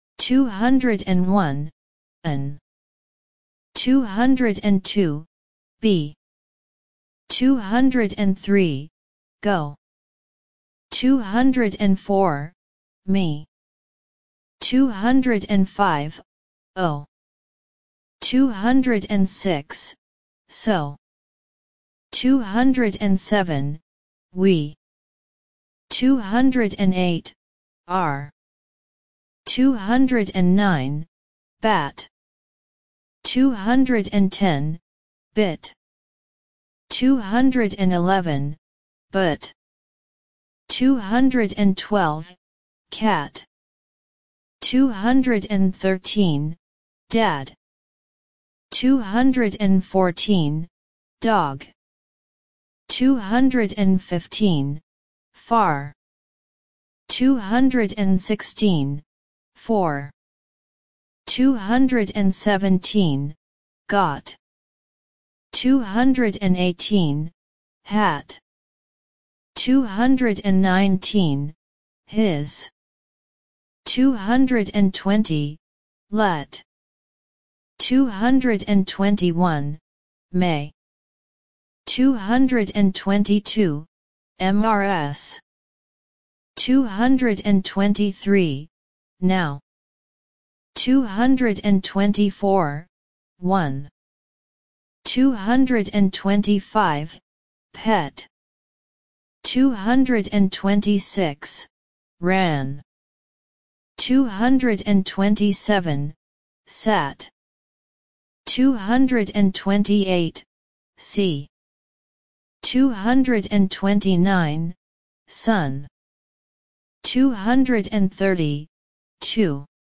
Listen and Repeat.
201 – 300 Listen and Repeat